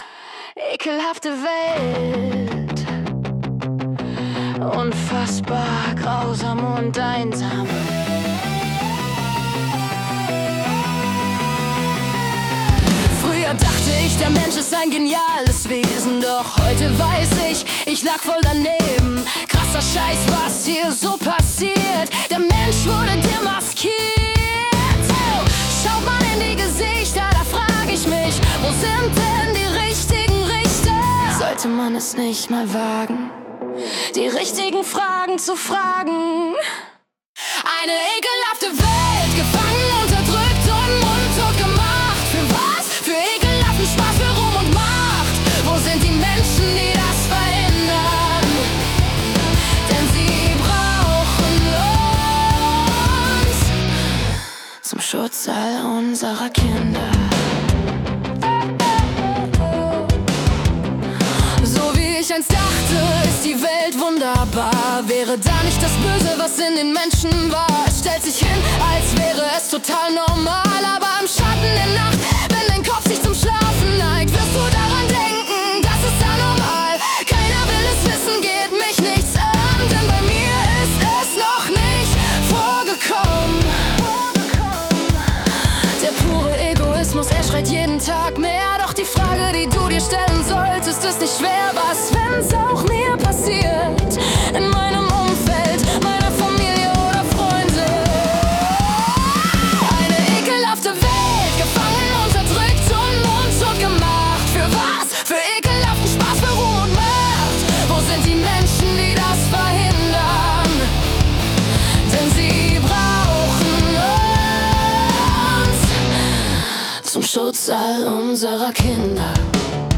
Hier eine Auswahl an Musik, die durch KI erzeugt wurde.